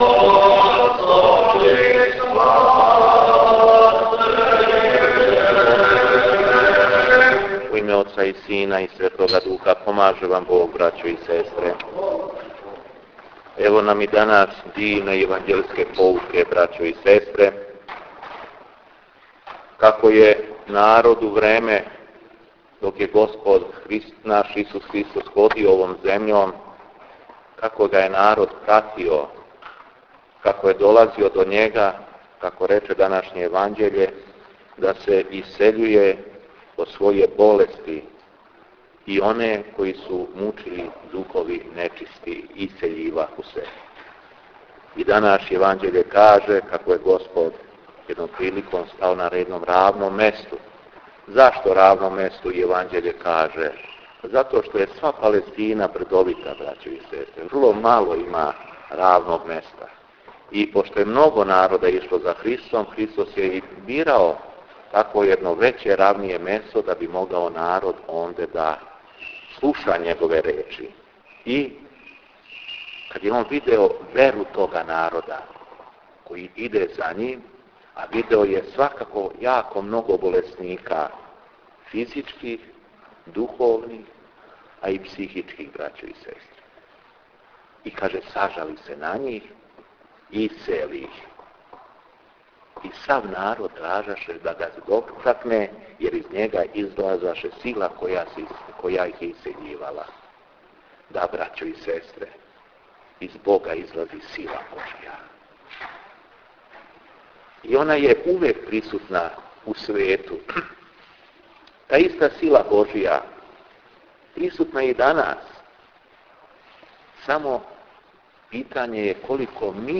Беседа епископа шумадијског Г. Јована у Старој цркви у Крагујевцу